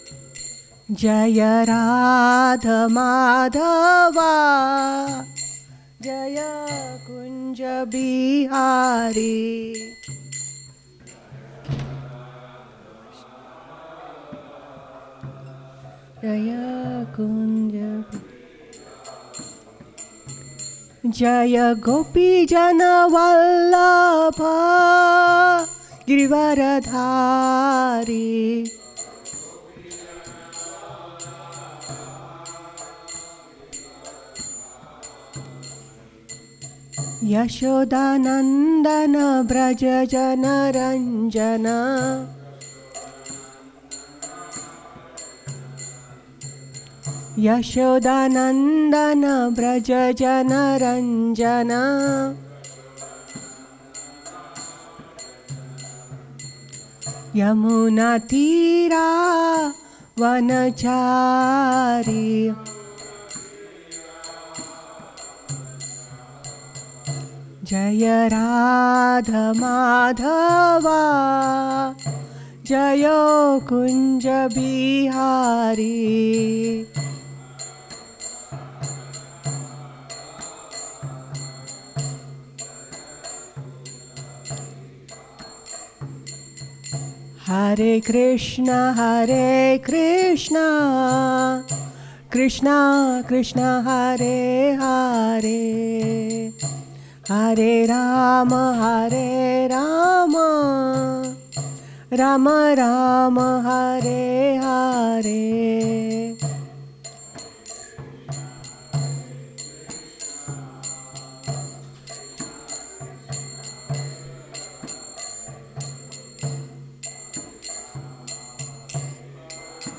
at the Hare Krishna temple in Alachua, Florida. The Srimad-Bhagavatam is a sacred Vaishnava text from India that narrates the history of God (Krishna) and His devotees.